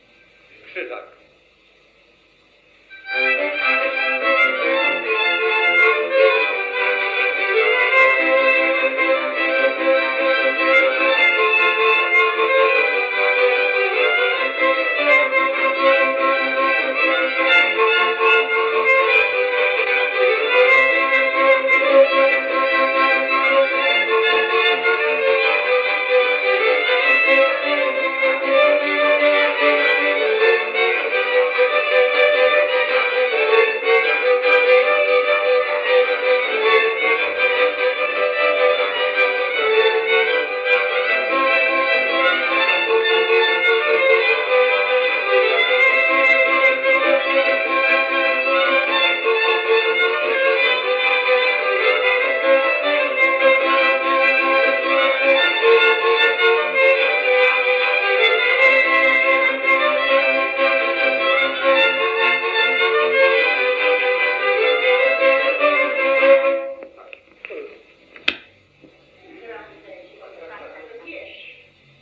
Instrumentalny 24 (Krzyżak) – Żeńska Kapela Ludowa Zagłębianki